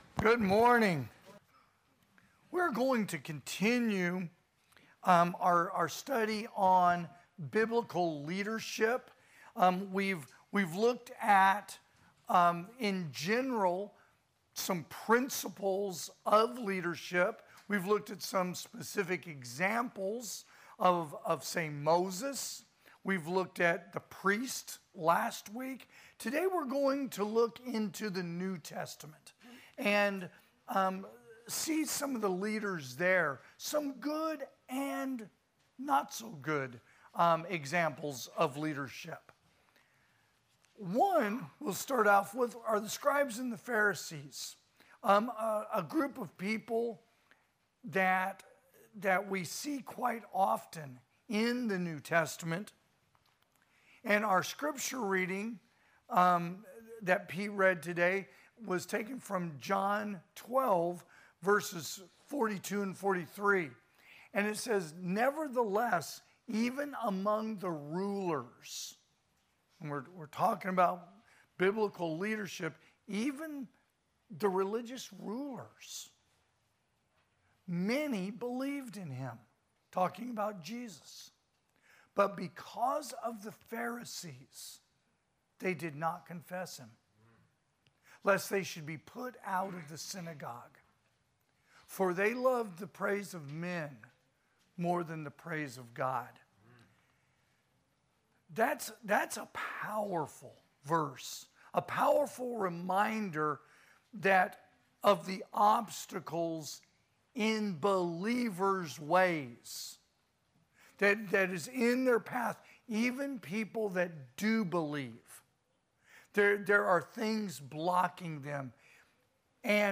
AM Worship